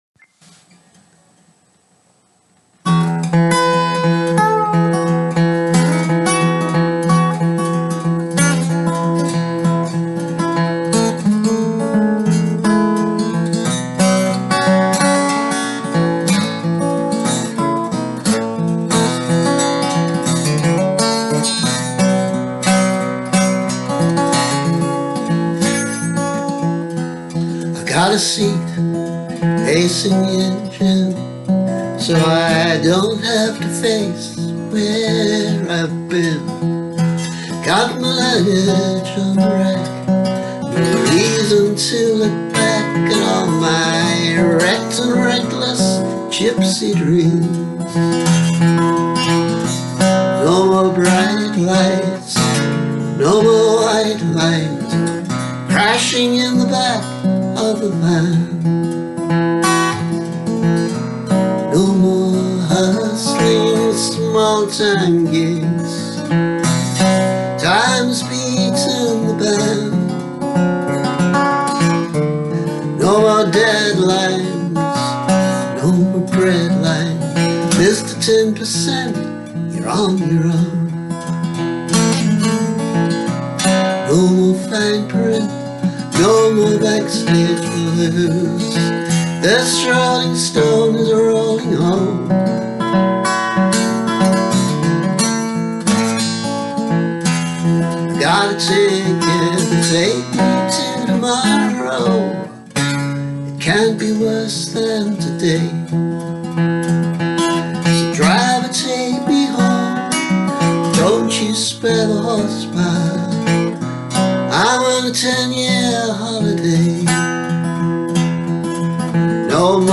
YouTube video version played in dropped D, no slide. From 2020.